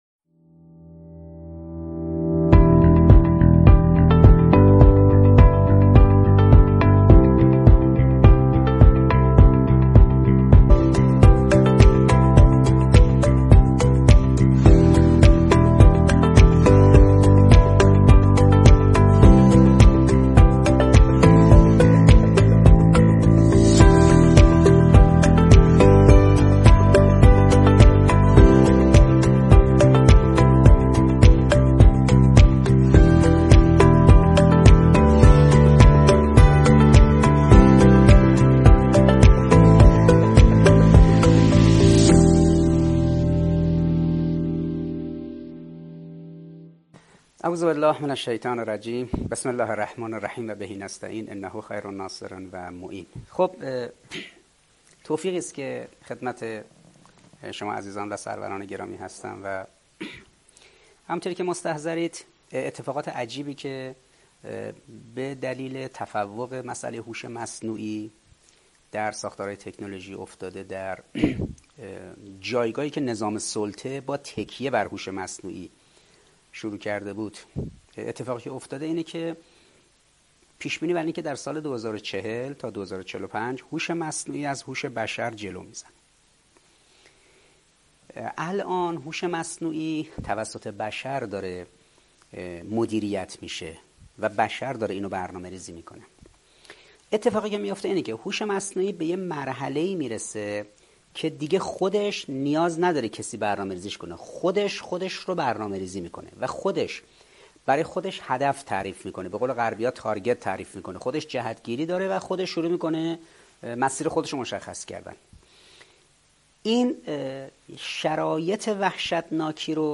سخنرانی استاد حسن عباسی با موضوع تکینه‌گی هوش مصنوعی